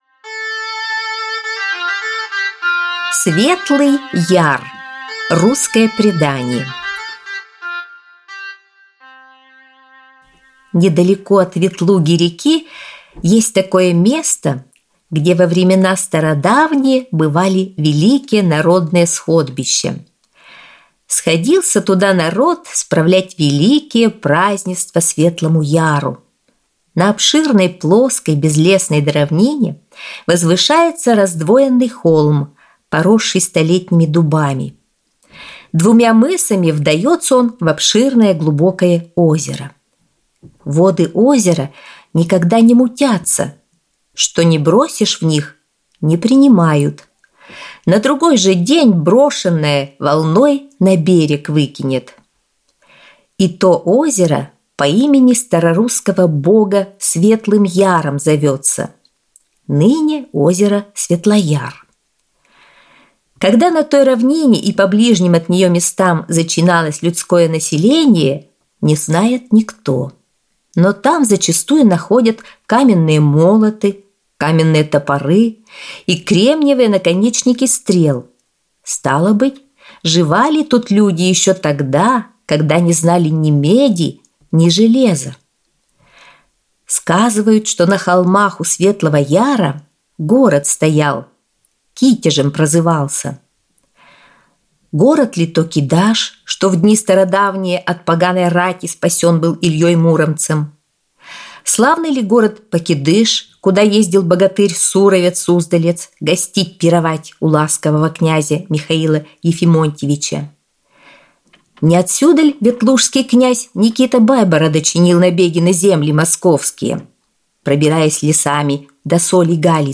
Аудиосказка «Светлый Яр»